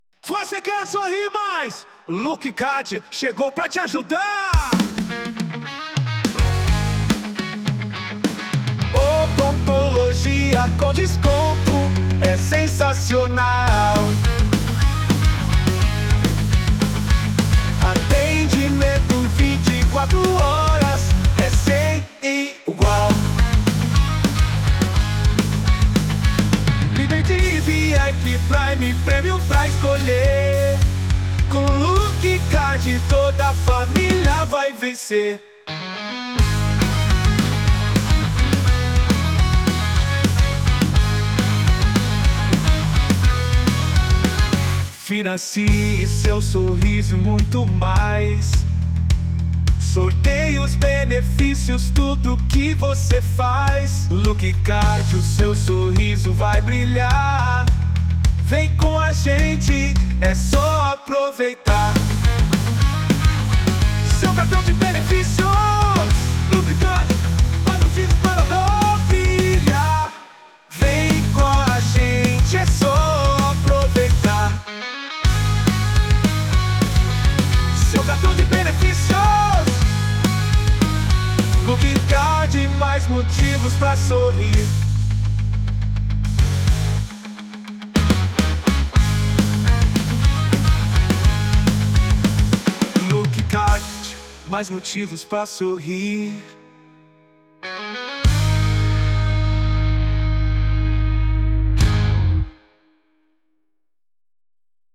Músicas para Marketing